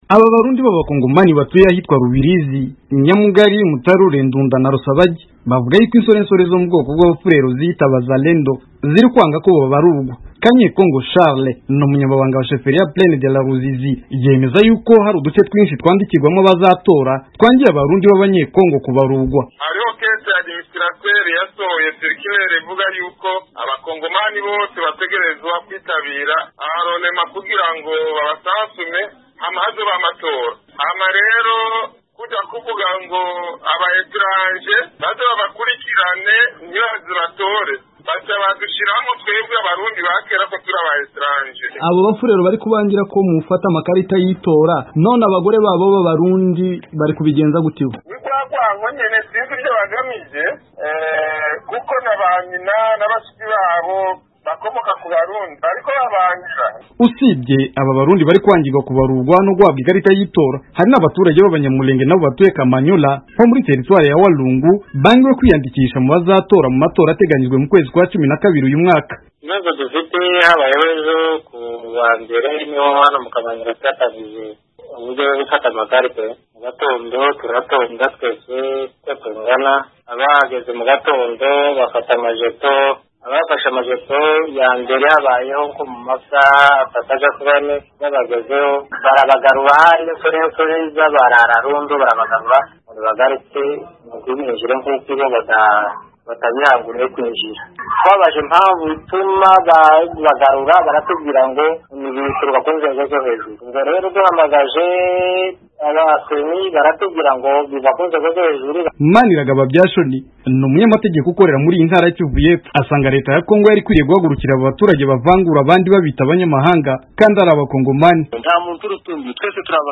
yabiteguyeho inkuru irambuye ushobora gukurikira mu ijwi rye hano hepfo.